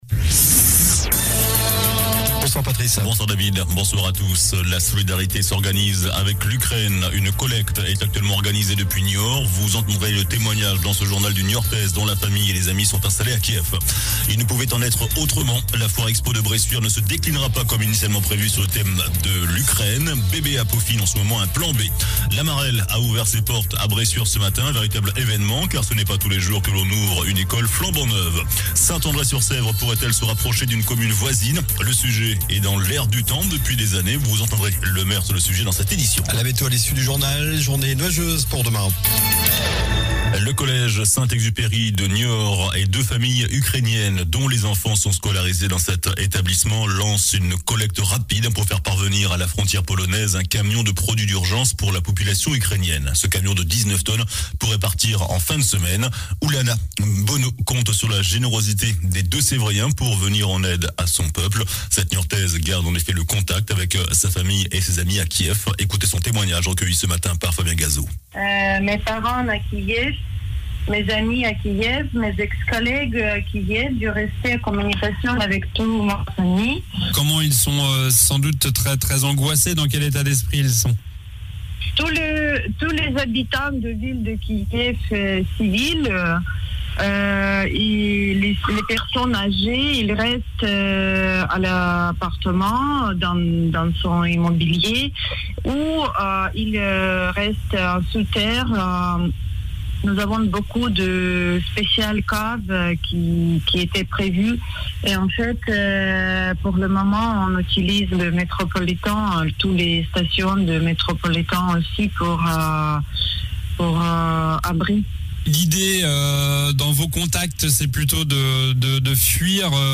JOURNAL DU LUNDI 28 FEVRIER ( SOIR )